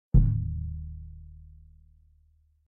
surdo-3.mp3